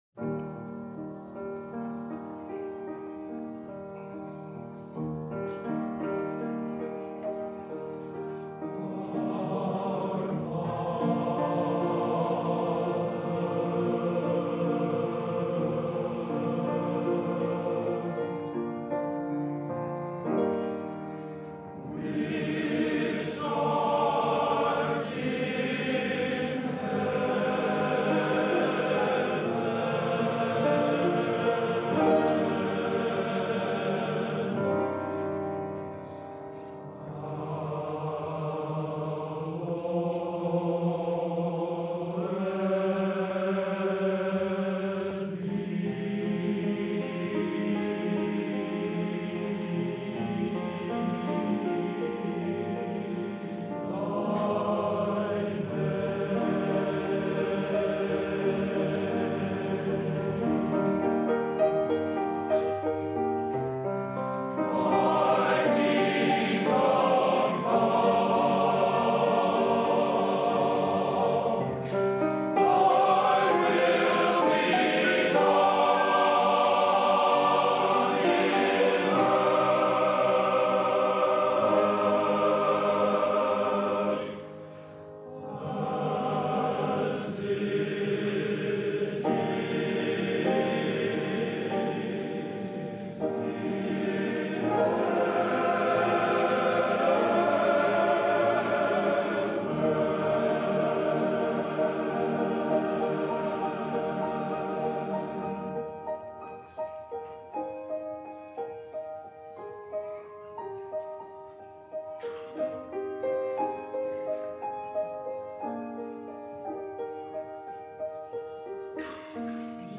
America, Our Heritage - BNC Plainsmen Spring Concert 1967